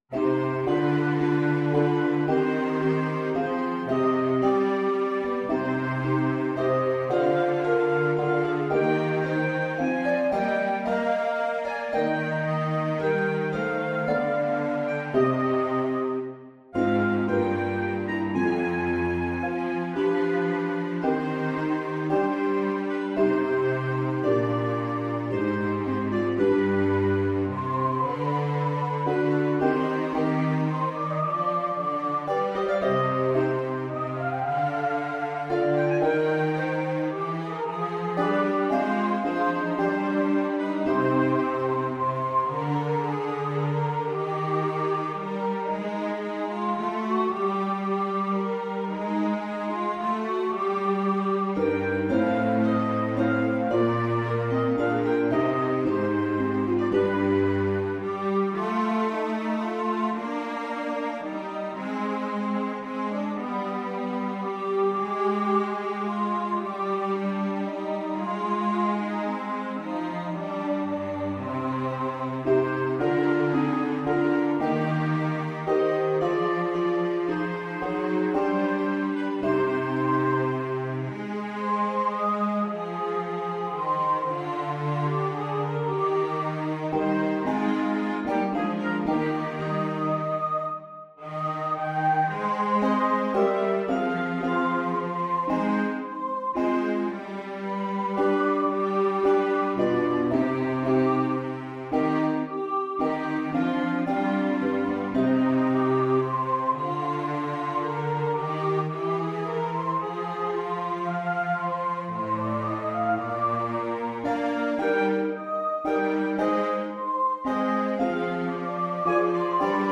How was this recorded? (MIDI)